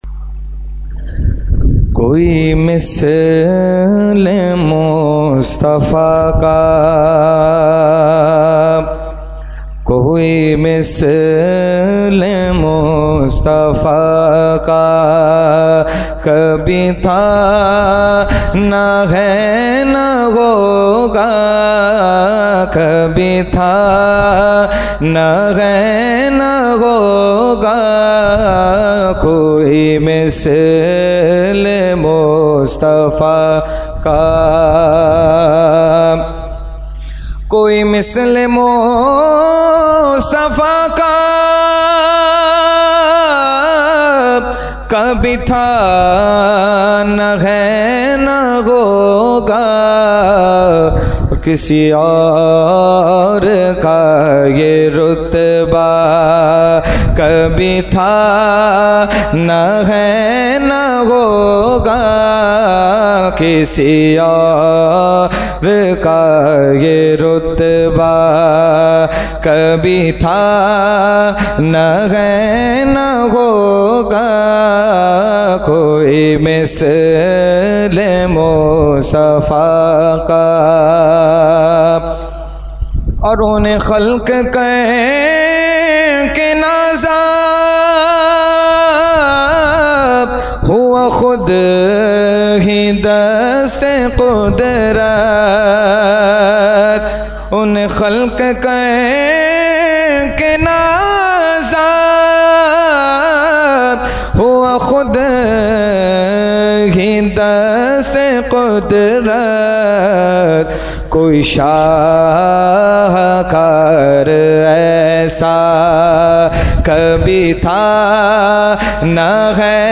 Category : Naat | Language : UrduEvent : Khatmul Quran 2020